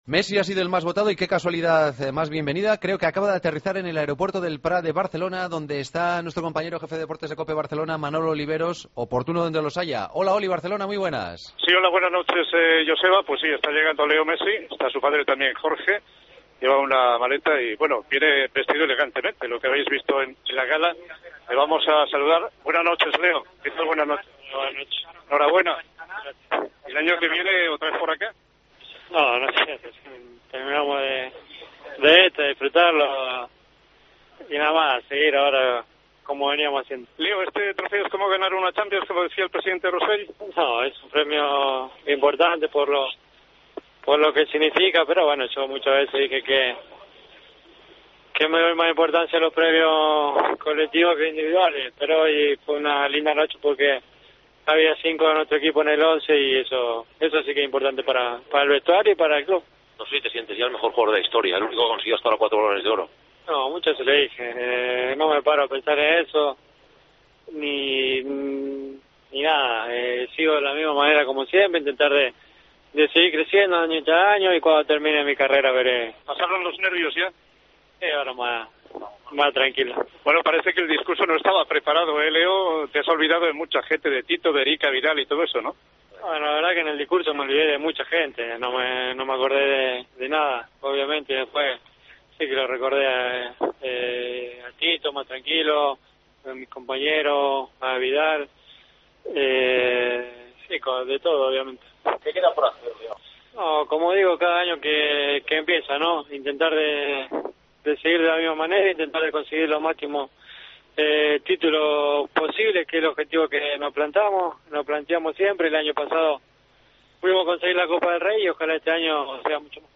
Redacción digital Madrid - Publicado el 08 ene 2013, 00:02 - Actualizado 14 mar 2023, 15:01 1 min lectura Descargar Facebook Twitter Whatsapp Telegram Enviar por email Copiar enlace El astro argentino habló para "el Partido de las 12" a su llegada a Barcelona, después de conseguir su cuarto Balón de Oro consecutivo.